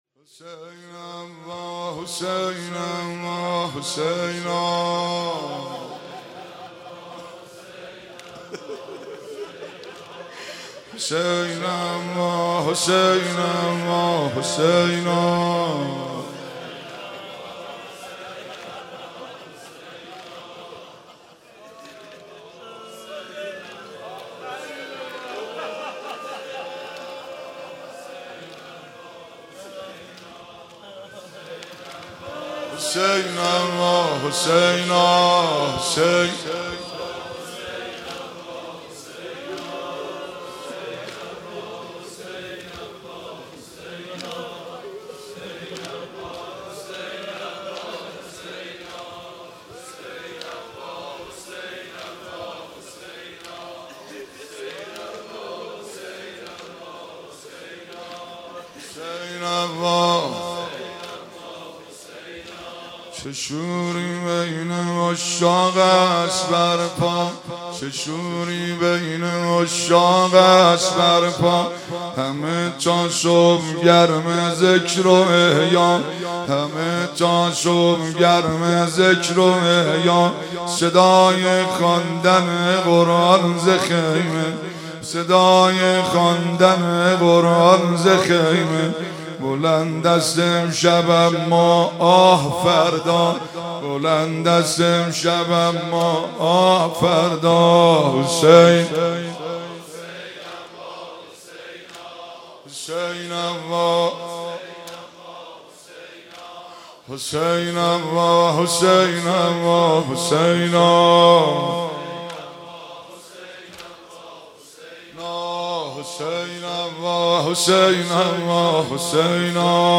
برادر غریب من برادر غریب من (شور)
در شب عاشورای 93
(روضه)
(زمینه)
(مناجات)